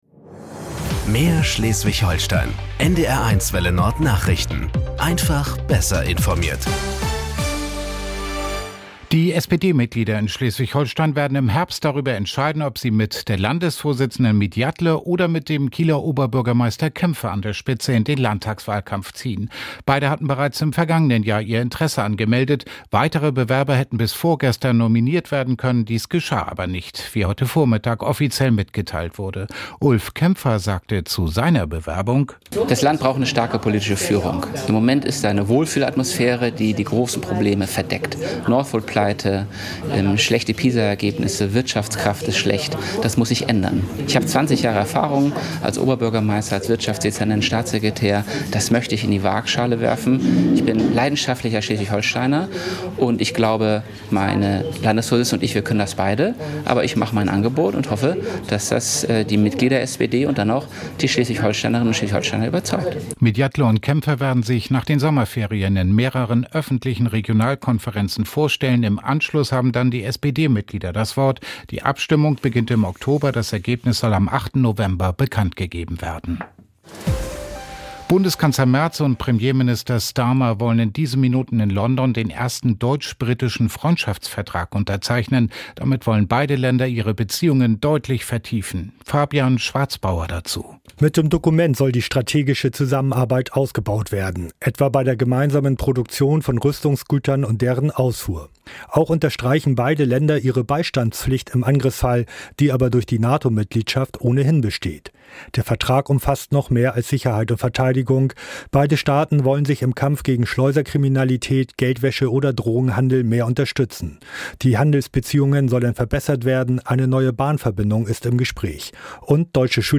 … continue reading 5 episodes # Tägliche Nachrichten # Nachrichten # NDR 1 Welle Nord